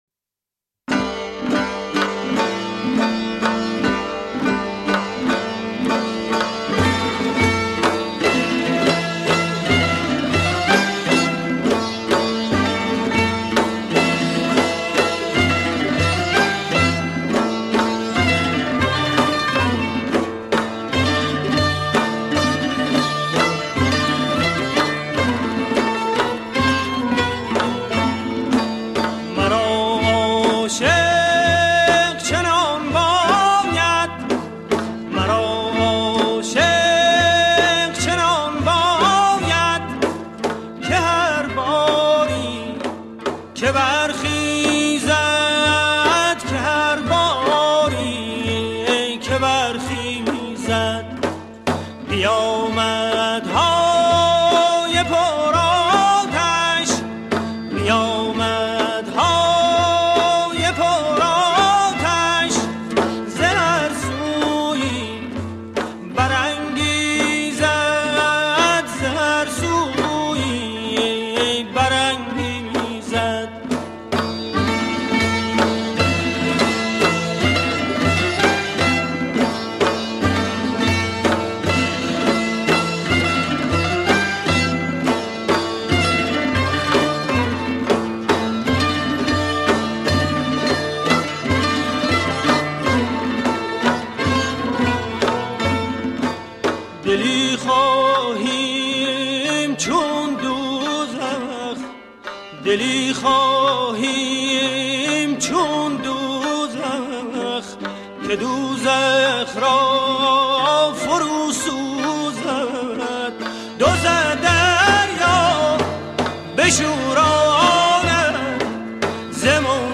دستگاه: شور